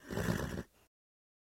Звуки фырканья лошади
На этой странице собраны разнообразные звуки фырканья лошадей — от игривого до раздраженного.